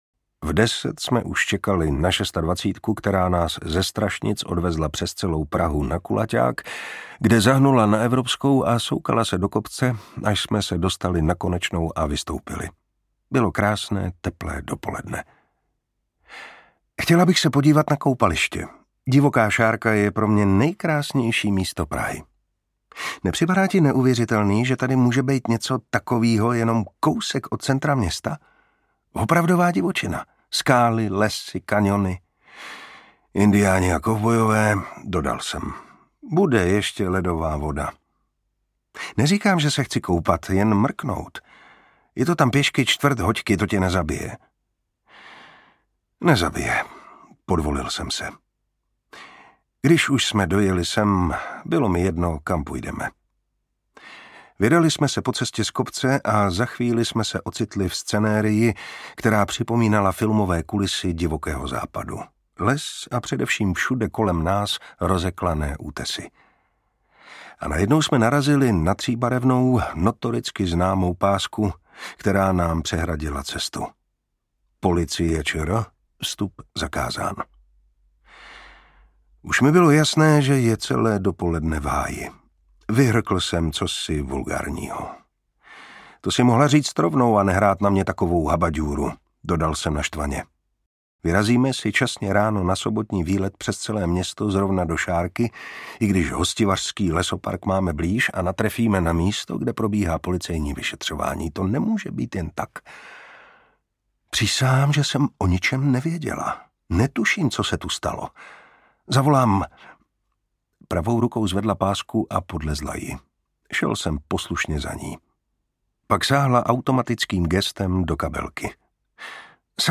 Audio knihaNahá s Davidovou hvězdou
Ukázka z knihy
Čte Martin Preiss.
Vyrobilo studio Soundguru.